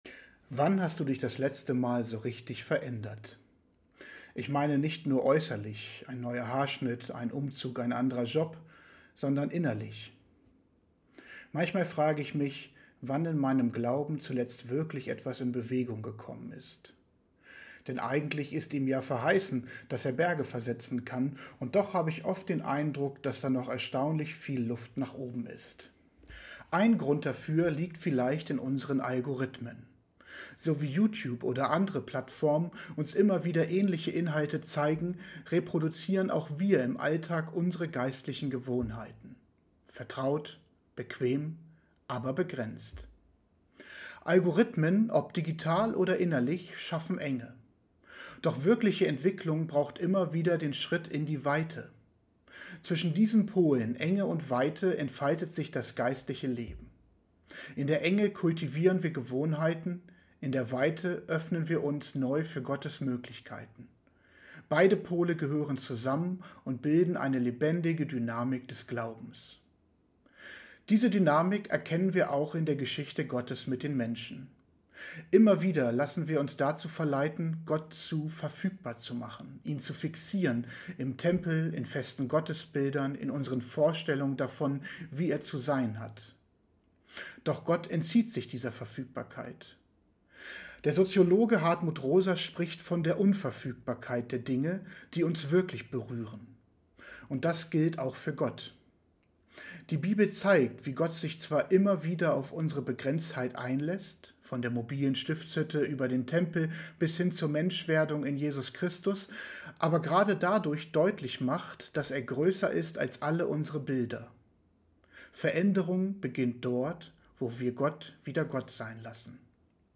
andacht_veraenderung.mp3